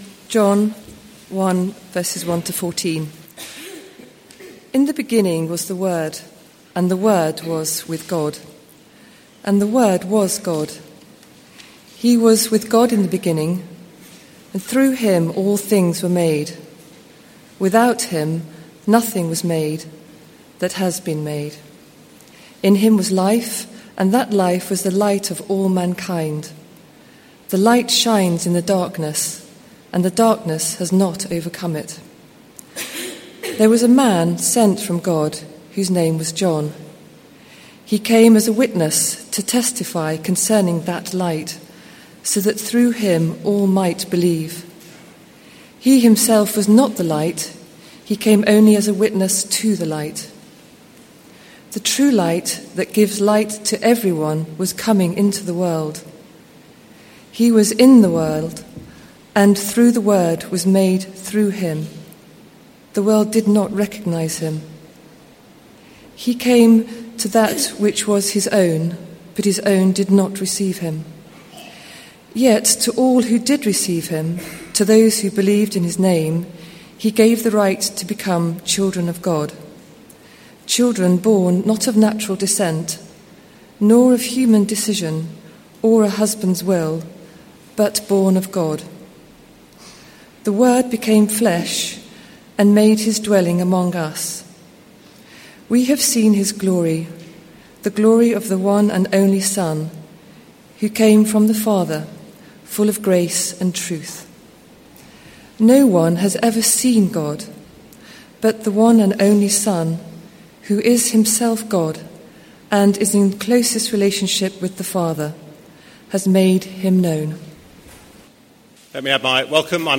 given at a Wednesday meeting
John 1 Carol Service 2014.mp3